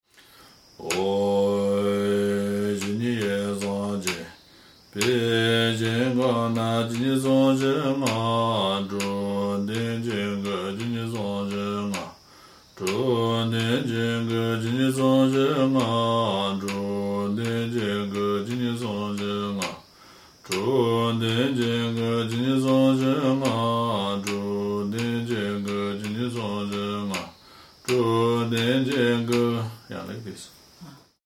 (This ké has no go-gyer)
how to count – 9 syllables